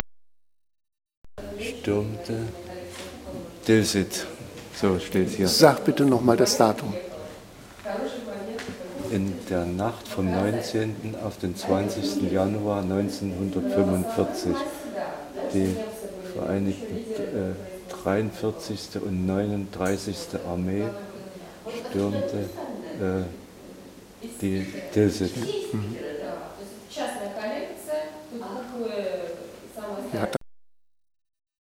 Museum der Geschichte der Stadt Sovetsk/Tilsit, ul. Pobedy 34, Sovetsk, Russland